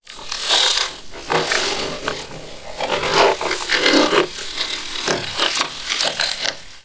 ALIEN_Communication_05_mono.wav